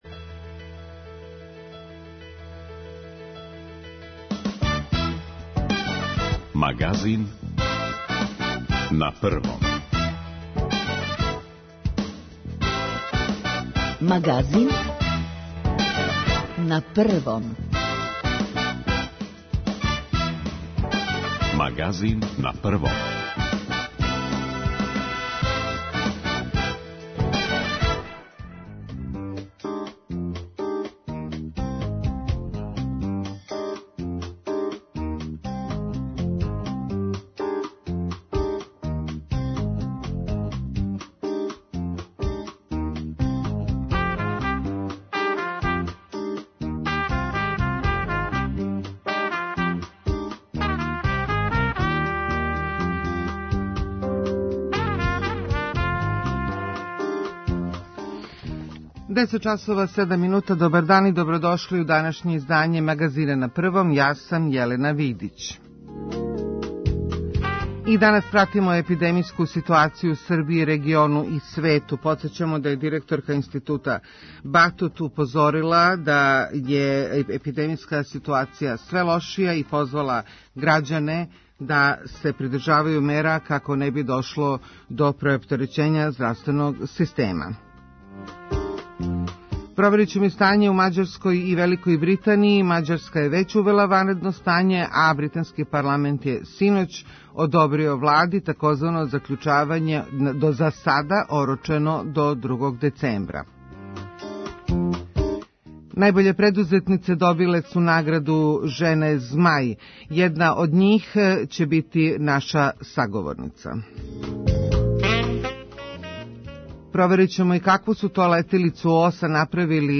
О стању у тим земљама јављају наши сарадници из Будимпеште и Лондона.